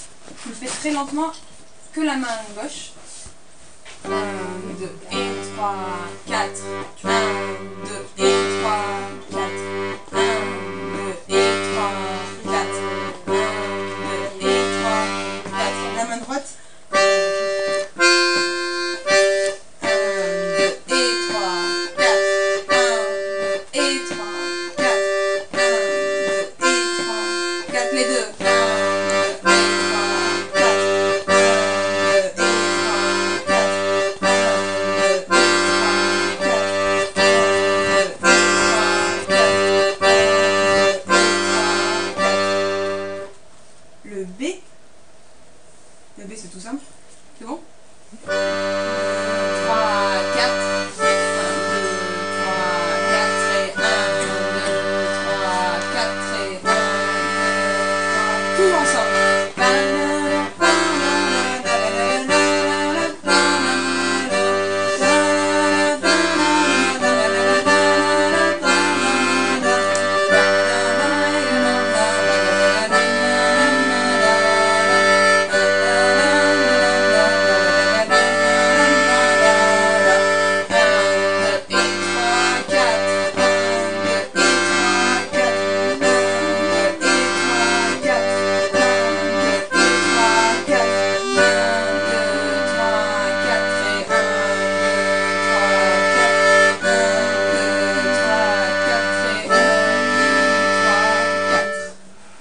l'atelier d'accordéon diatonique
en divisant chaque mesure en 8 double croches. La basse est sur le 1,  accord sur le 4, puis sur le 7